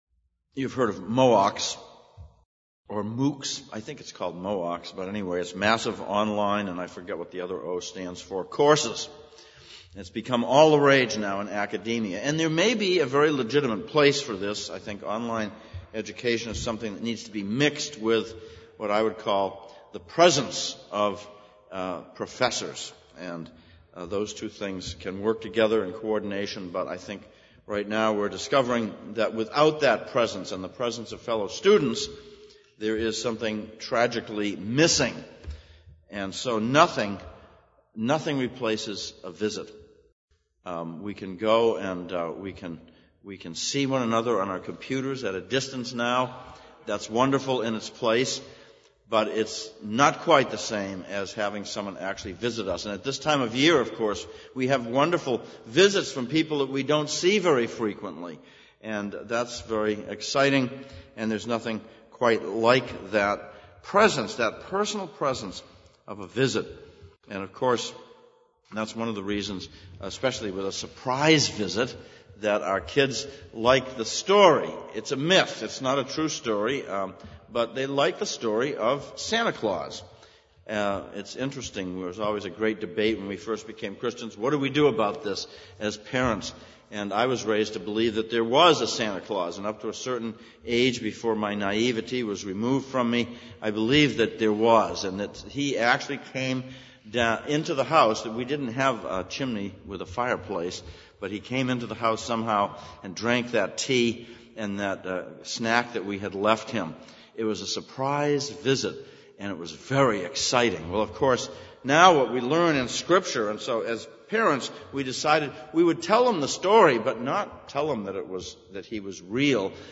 Service Type: Sunday Evening Sermon